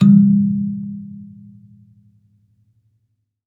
kalimba_bass-G#2-ff.wav